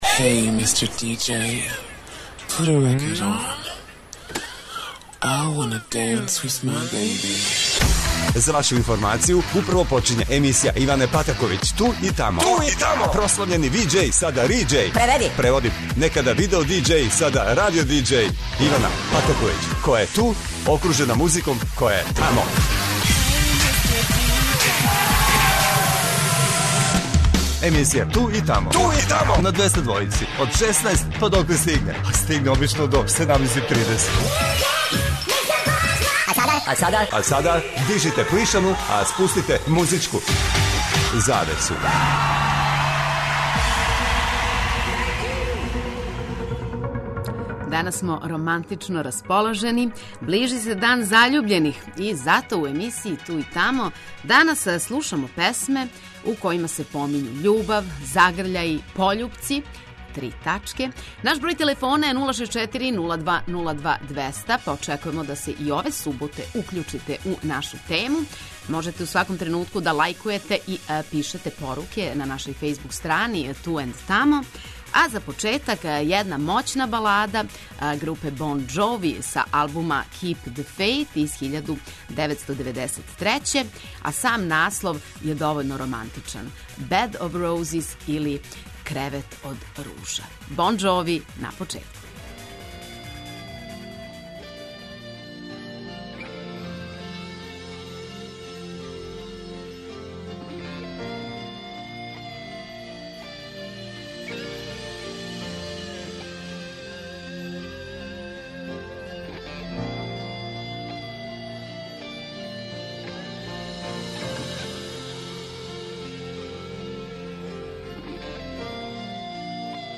Ове суботе емисија је у бојама љубави. У сусрет Дану заљубљених за слушаоце Двестадвојке одабрали смо песме које у наслову имају романтику, љубав, пољупце, срца и загрљаје...